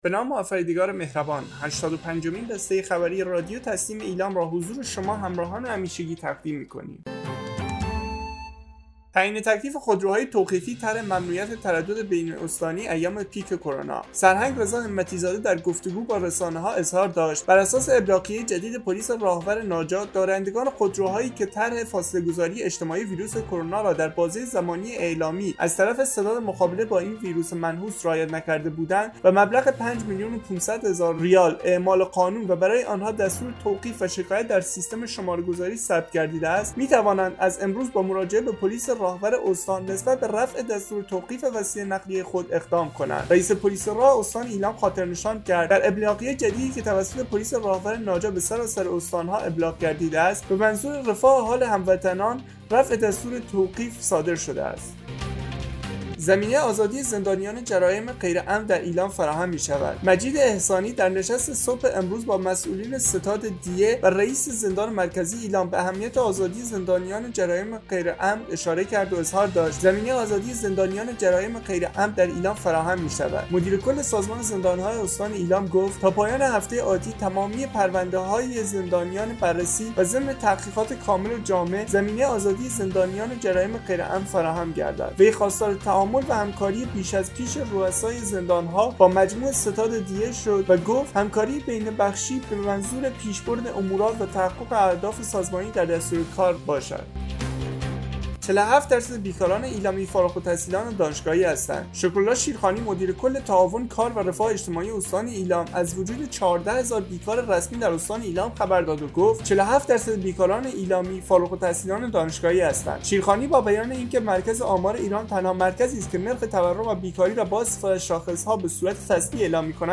به گزارش خبرگزاری تسنیم از ایلام, هشتاد و پنجمین بسته خبری رادیو تسنیم استان ایلام با خبرهایی چون زمینه آزادی زندانیان جرائم غیر عمد در ایلام فراهم می‌شود‌، تعیین تکلیف خودروهای توقیفی طرح ممنوعیت تردد بین استانی ایام پیک کرونا، 47 درصد بیکاران ایلامی فارغ‌التحصیلان دانشگاهی هستند، منتشر شد.